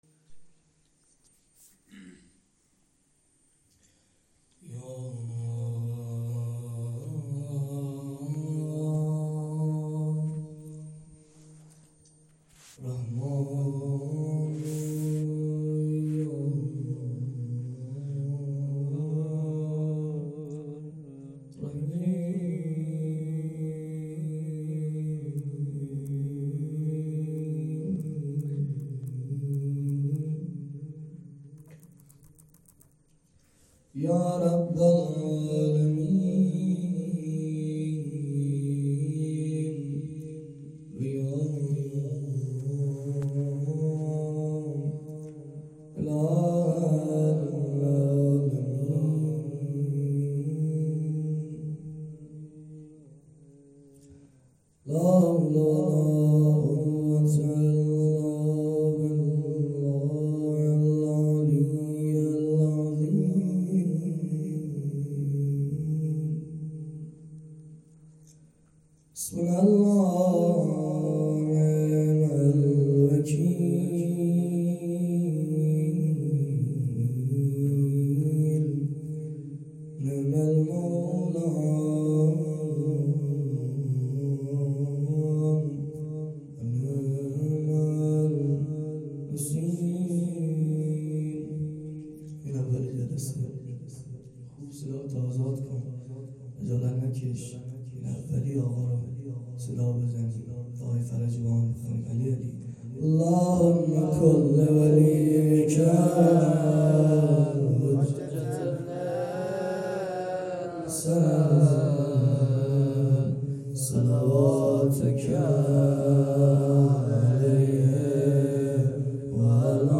جلسه هفتگی
روضه